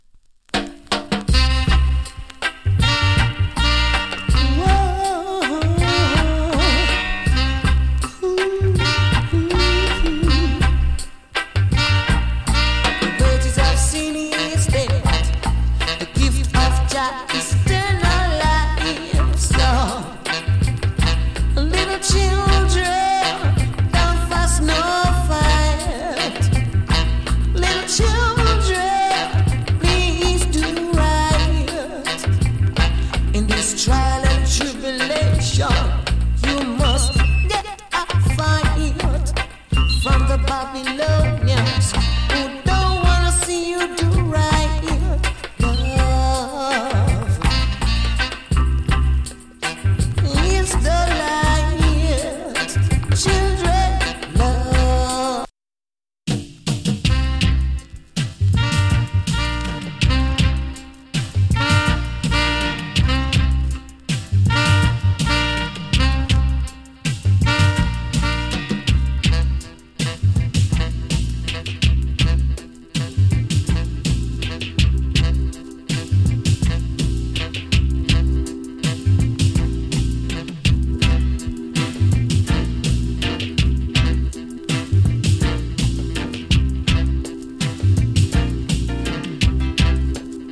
プレスノイズわずかに有り。